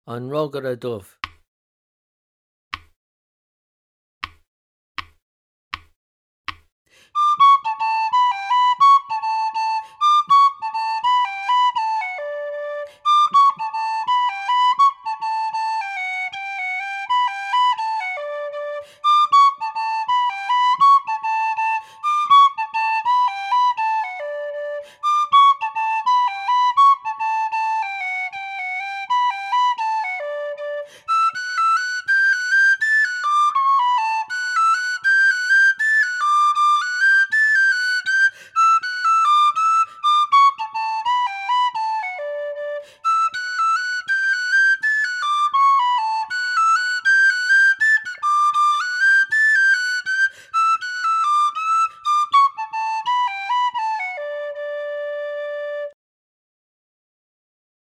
Irish Traditional Session Music, Book 2